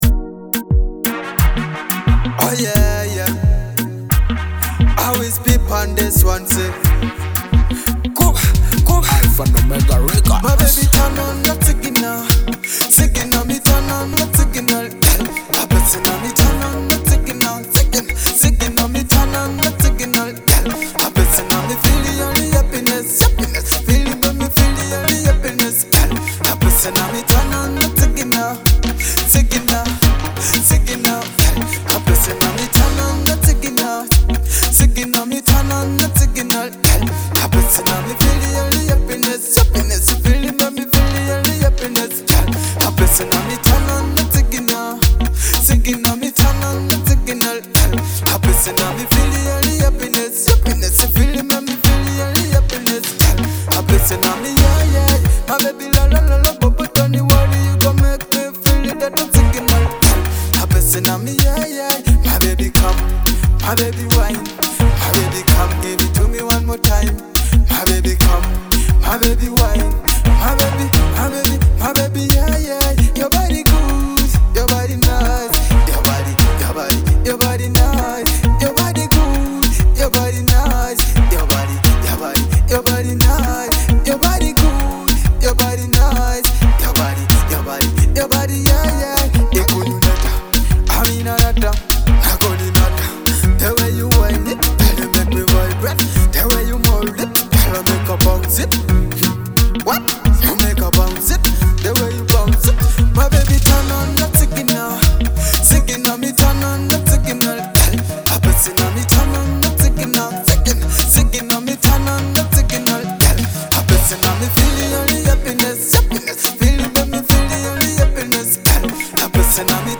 vibrant Afrobeat track
fresh, uplifting, and irresistibly danceable.